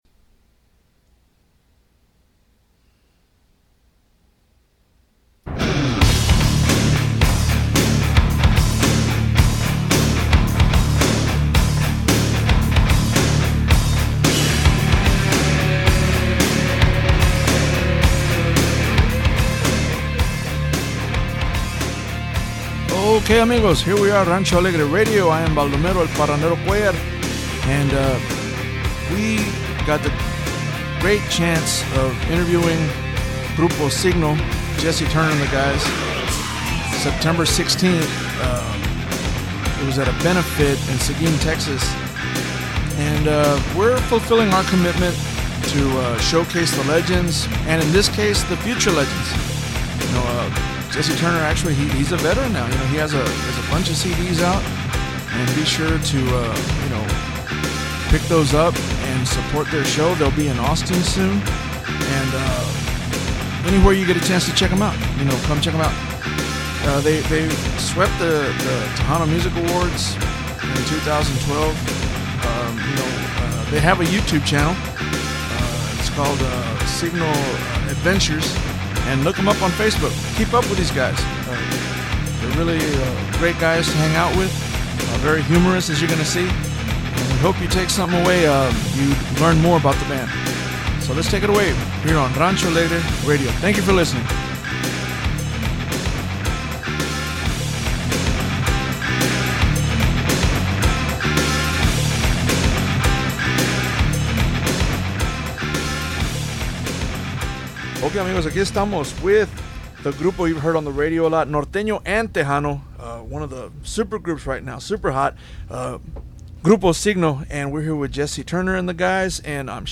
Rancho Alegre Interview - Siggno